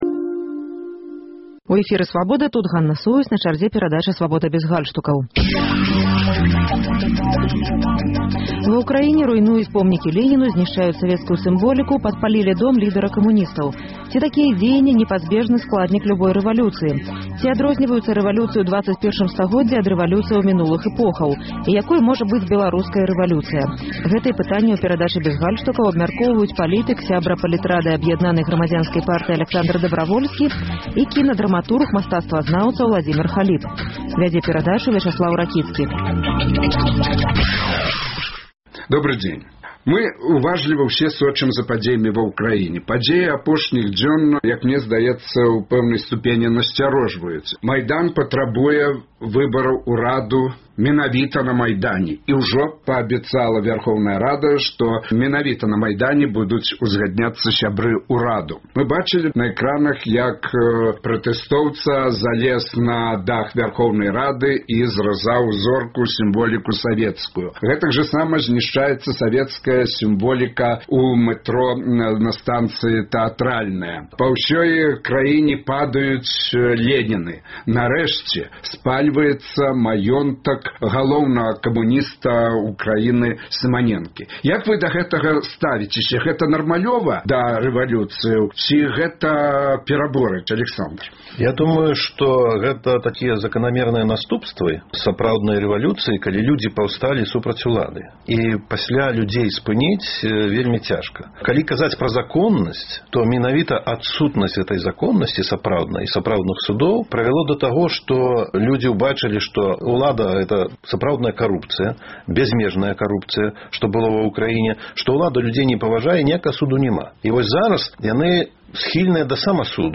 Рэвалюцыянэры руйнуюць помнікі Леніну, зьнішчаюць савецкую сымболіку, падпалілі дом лідэра камуністаў. Ці такія дзеяньні — непазьбежны складнік любой рэвалюцыі? У дыскусіі бяруць удзел палітык